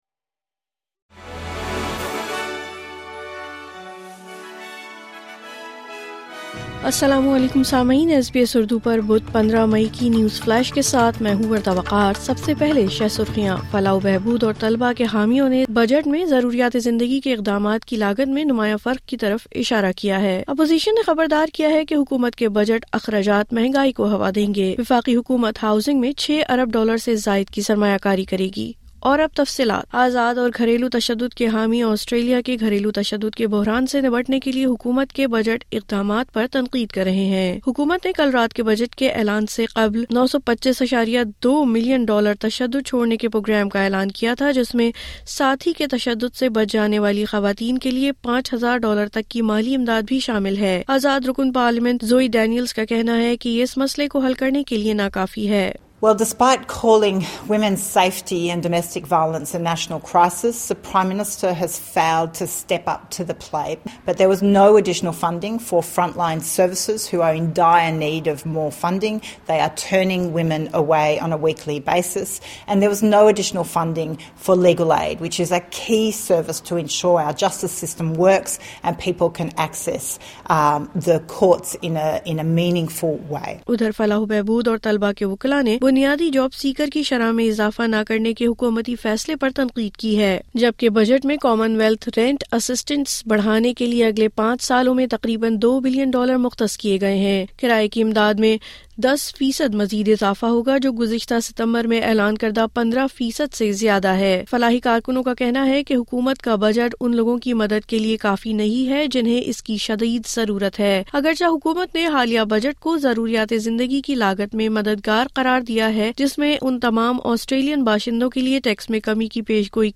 نیوز فلیش:15 مئی 2024: وفاقی بجٹ گذشتہ شب پیش کر دیا گیا اپوزیشن کی کڑی تنقید